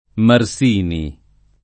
[ mar S& ni ]